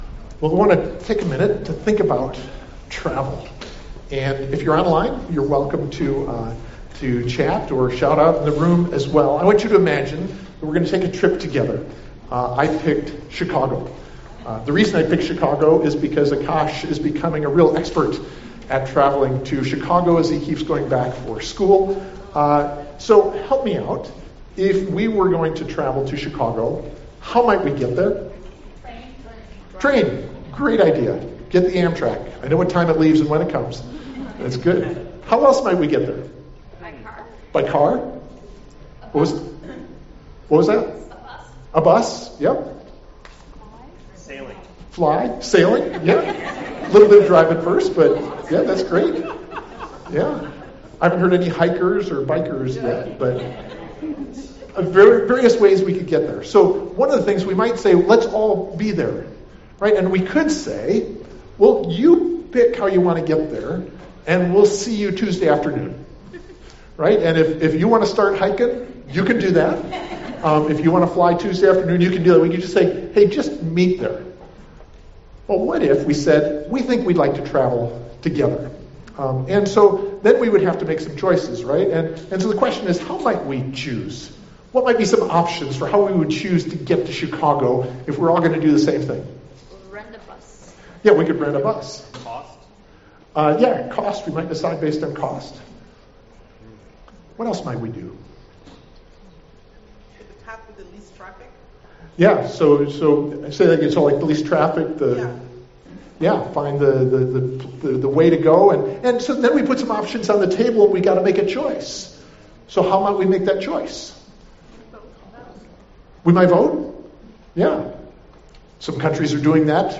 God’s New House (Ephesians 2:10-22) October 27, 2024 Watch Listen Save Sermon Handout Sermon Slides Ephesians 2:10–22 Audio (MP3) 12 MB Previous Walking with God [Genesis 5] Next Taken Away (Leviticus 16)